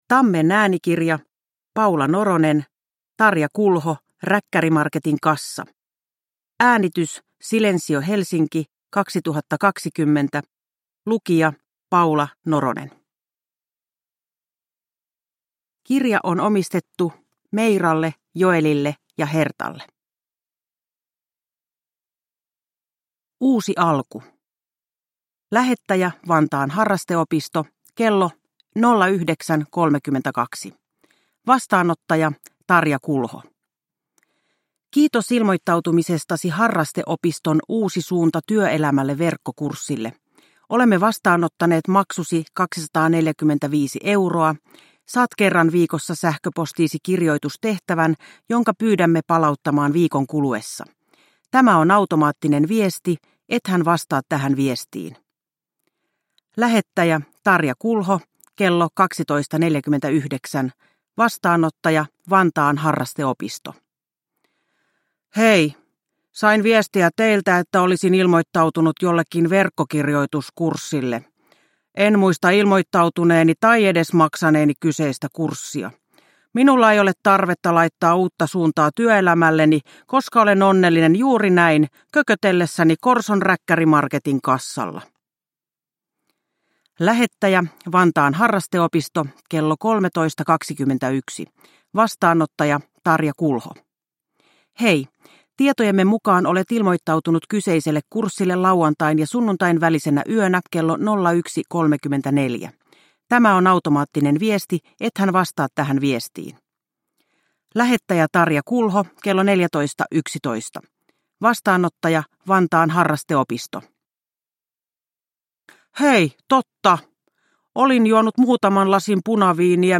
Tarja Kulho ? Räkkärimarketin kassa – Ljudbok